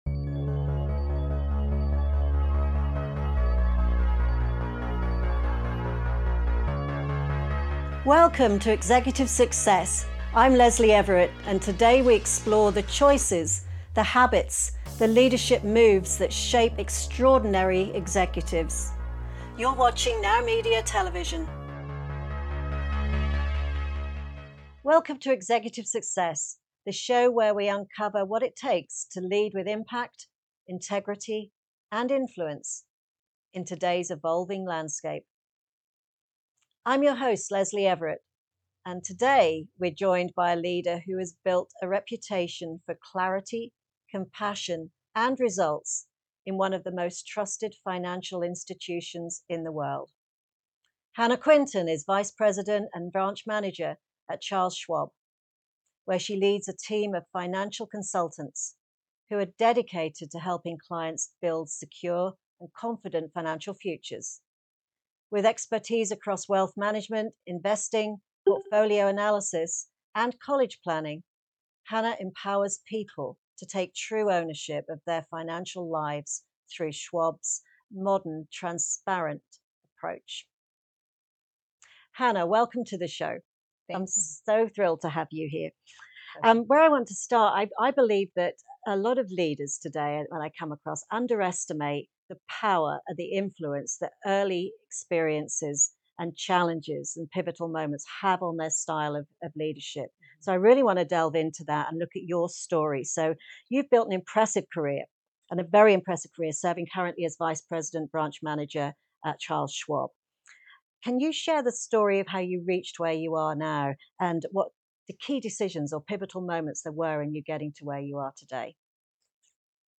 for an inspiring and insightful conversation on leadership, growth, and resilience.